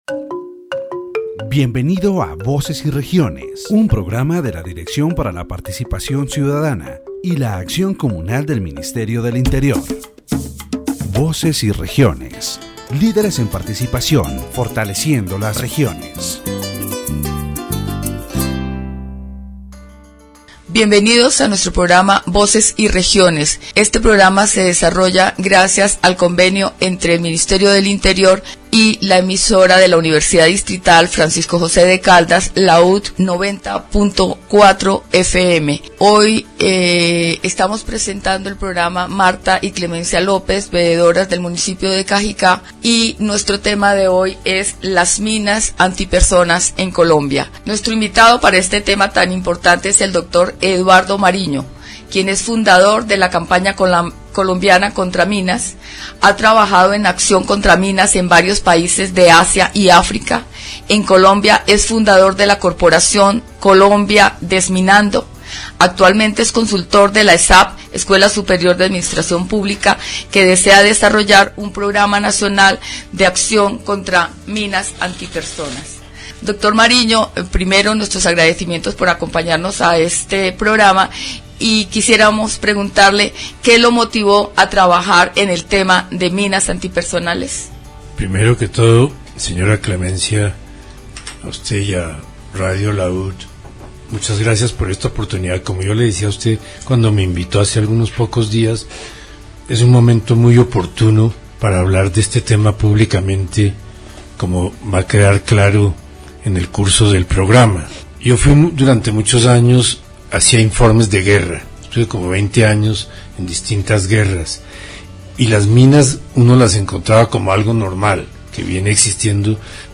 In this section of the Voces y Regiones program, the discussion will focus on anti-personnel mines in Colombia. The interviewee shares their experience with these devices during the 1990s in Asia and Africa, talking about the injured and mutilated victims, as well as the paralysis these mines caused in rural areas. The conversation also highlights the importance of demining in Colombia, addressing the Ottawa Convention and the victims affected by both military forces and the civilian population, including children.